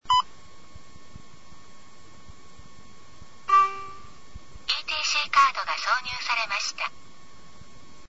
DIU-9000 音声案内　（MP3録音ファイル）